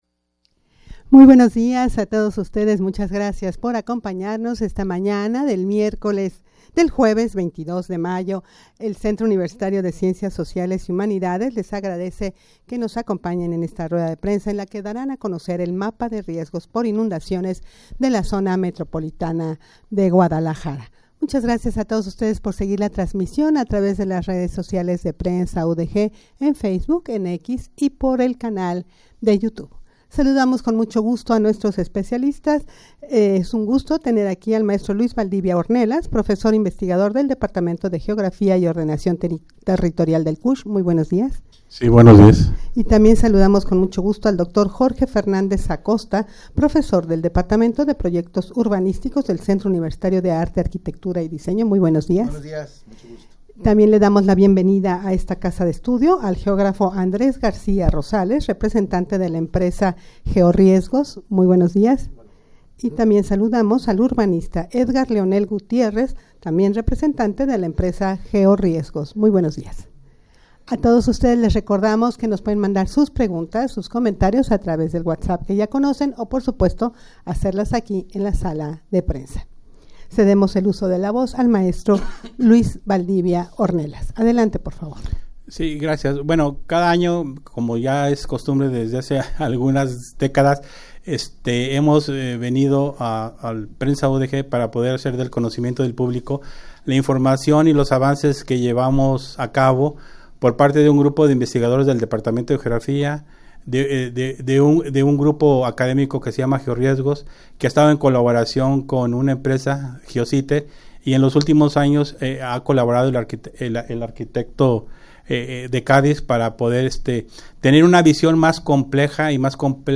Audio de la Rueda de Prensa
rueda-de-prensa-para-dar-a-conocer-el-mapa-de-riesgos-por-inundaciones-de-la-zmg.mp3